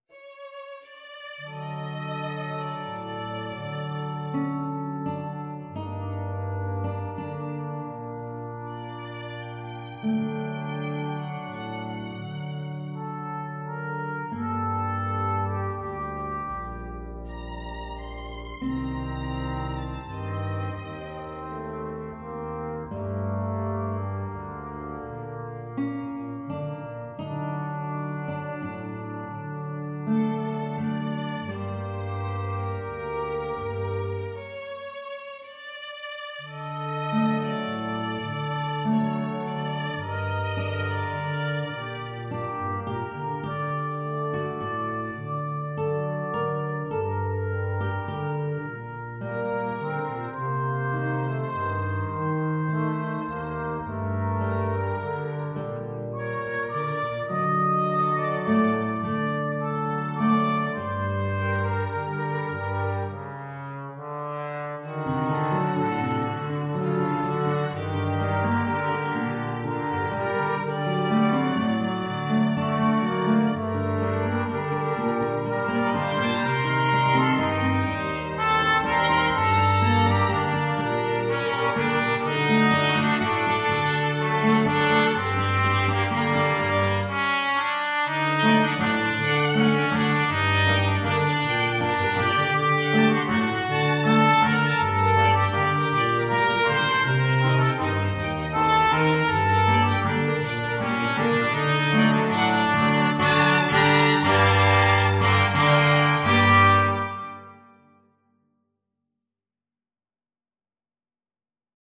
Violin
Guitar (Chords)
Trumpet
Trombone
Tuba
3/4 (View more 3/4 Music)
Slow Waltz = c. 84
Russian